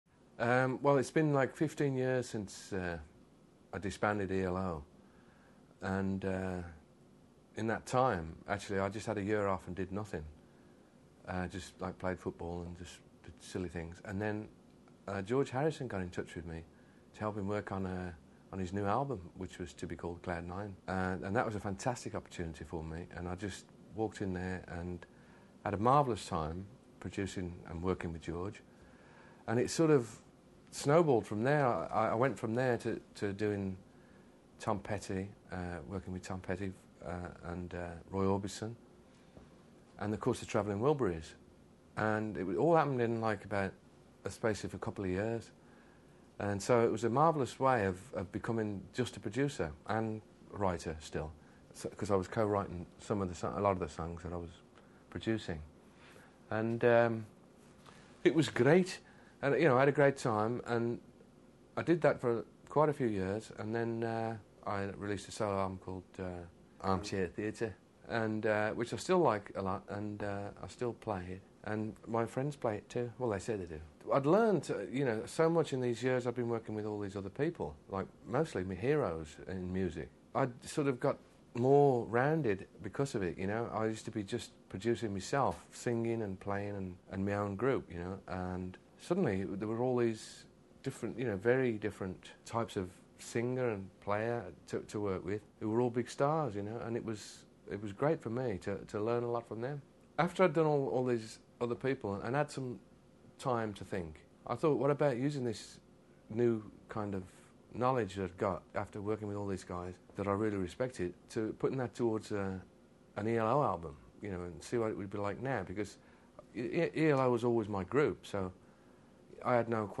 ELO Interview Disc